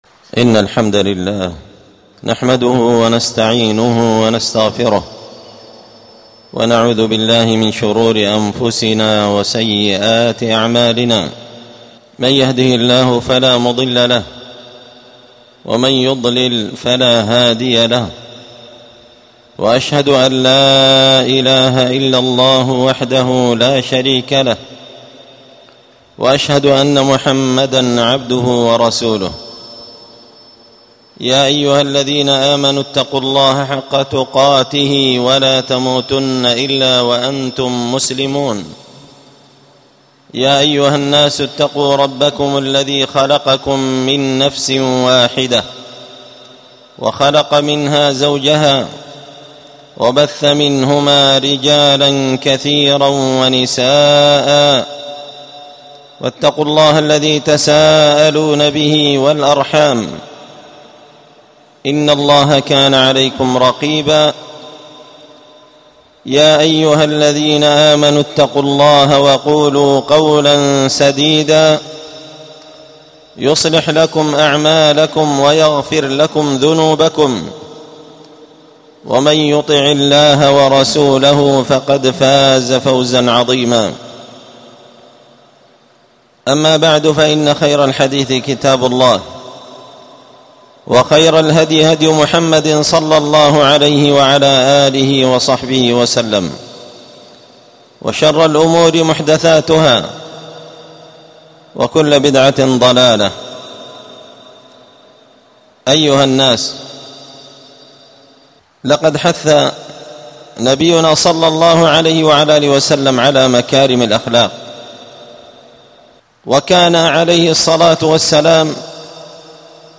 خطبة جمعة بعنوان: {التواضع سبيل الرفعة}
ألقيت هذه الخطبة بدار الحديث السلفية بمسجد الفرقان